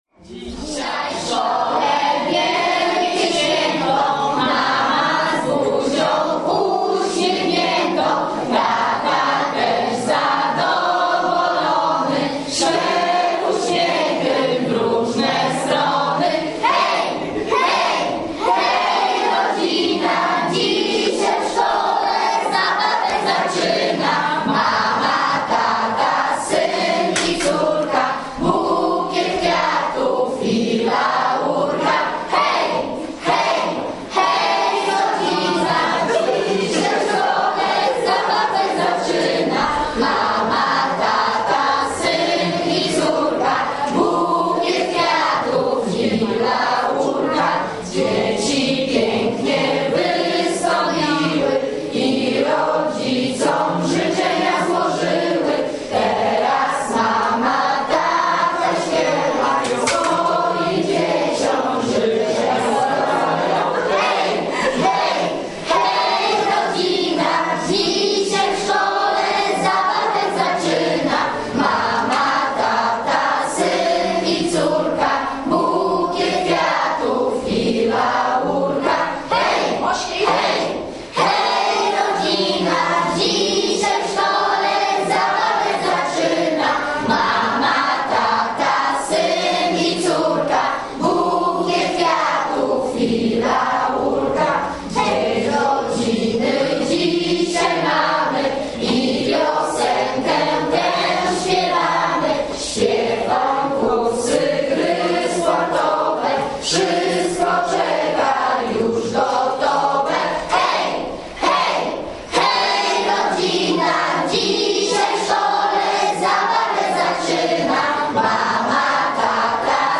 Miłym akcentem świętowania było też wspólne zaśpiewanie piosenki do melodii „Hej sokoły". Słowa rodzice otrzymali w trakcie uroczystości i mieli zaledwie kilka chwil, by się do śpiewania przygotować.